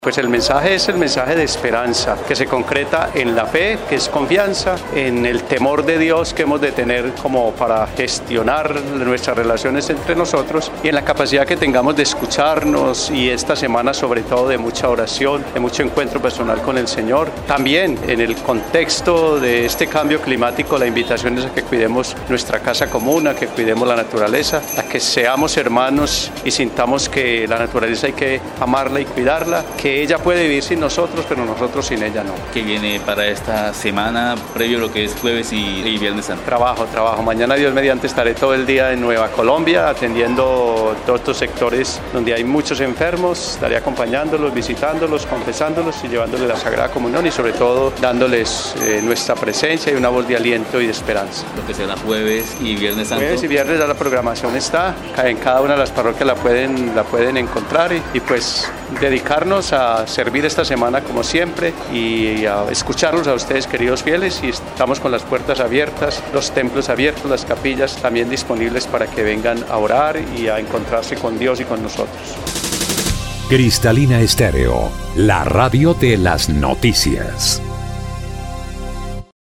Monseñor Omar de Jesús Mejía Giraldo, Arzobispo de la Arquidiócesis de Florencia, dijo que, durante estos días de recogimiento espiritual, se buscará llegar a los corazones de todas las personas que asistan a los actos religiosos programadas para tal fin.